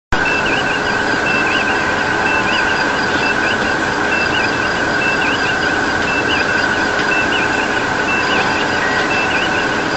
Computer noise in background